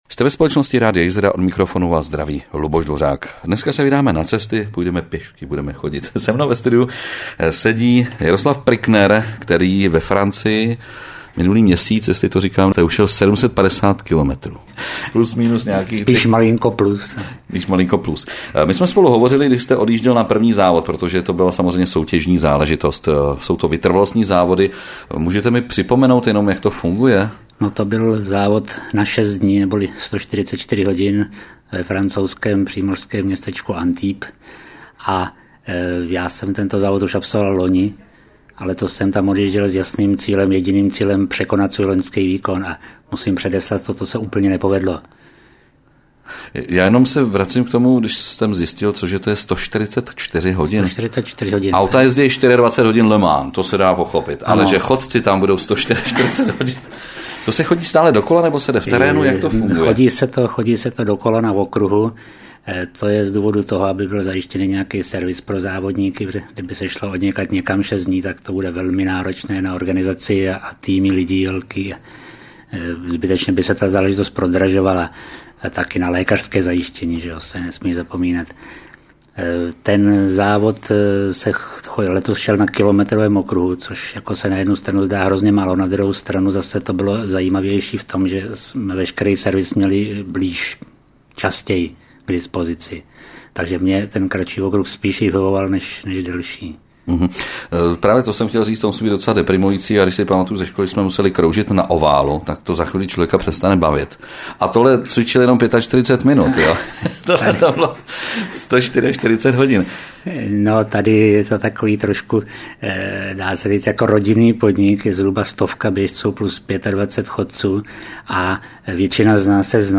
Rozhovor v Rádiu Jizera - 1. část